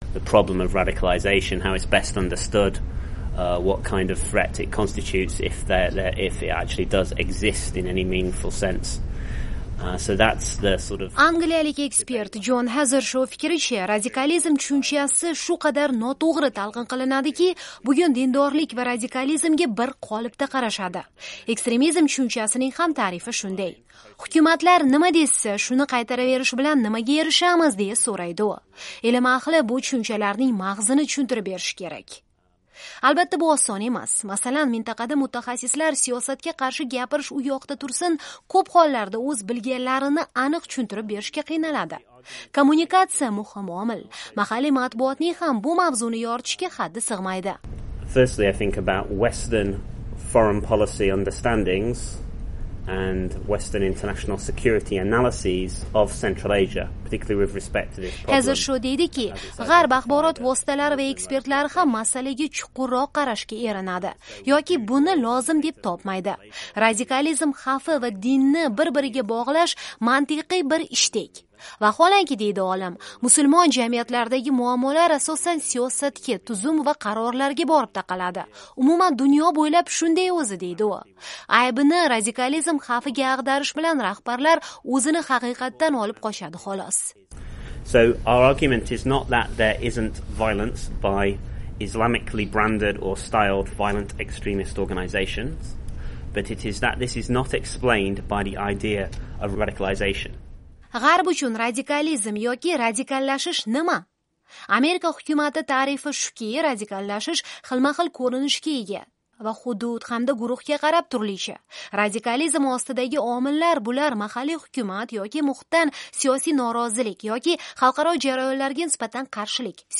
Jorj Vashington universitetida o'tgan ekspert muhokama